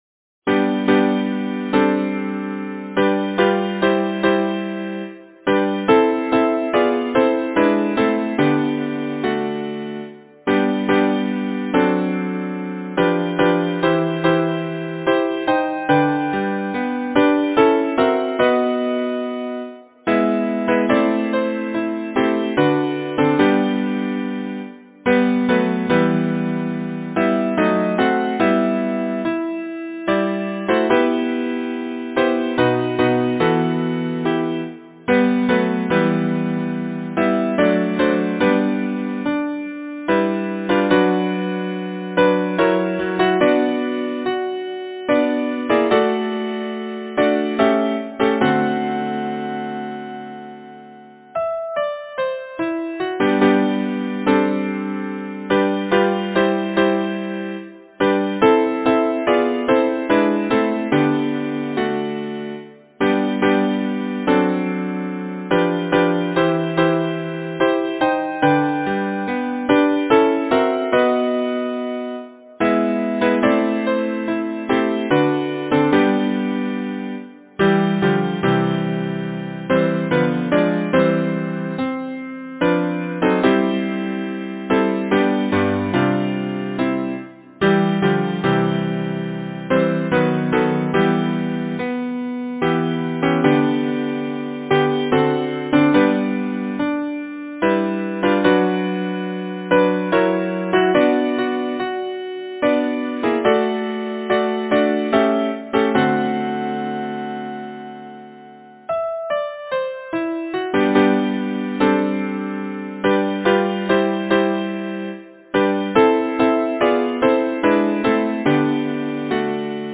Title: When hands meet Composer: Ciro Pinsuti Lyricist: Edward Oxenford Number of voices: 4vv Voicing: SATB Genre: Secular, Partsong
Language: English Instruments: A cappella